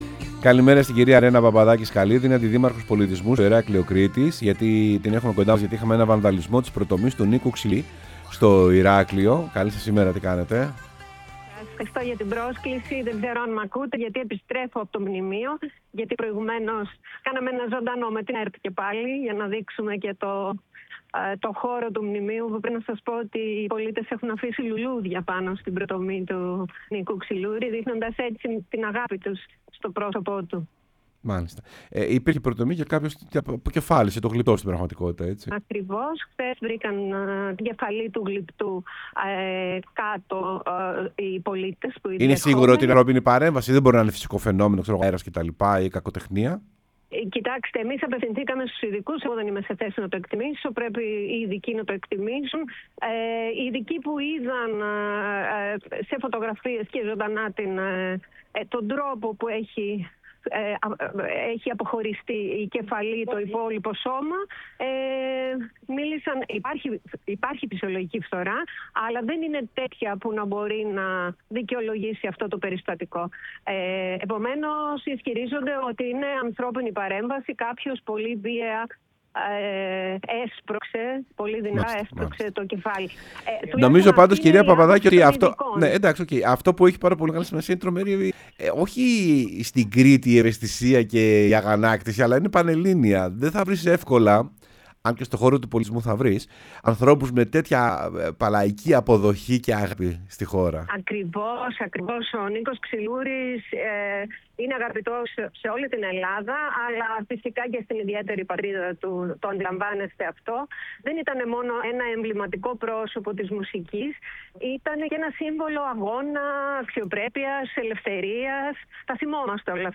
Η Ρένα Παπαδάκη – Σκαλίδη, αντιδήμαρχος Πολιτισμού Ηρακλείου Κρήτης, μίλησε στην εκπομπή «Σεμνά και Ταπεινά»